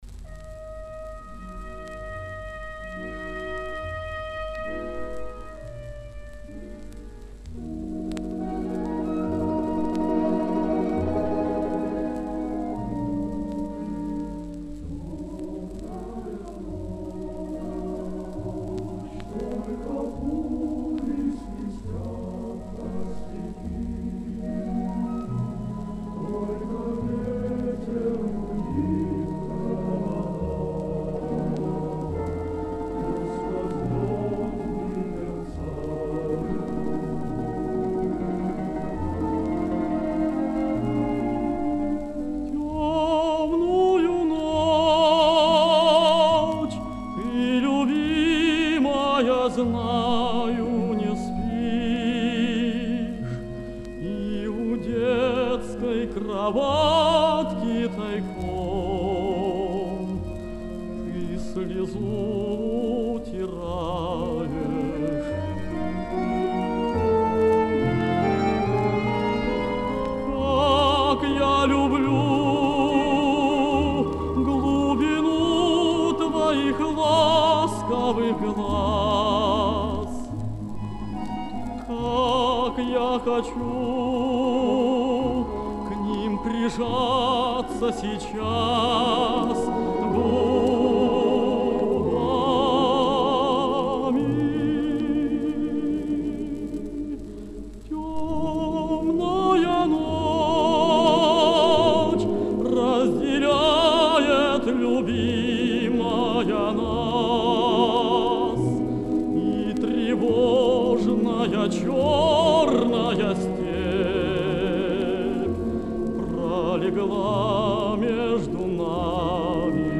Другой вариант исполнения.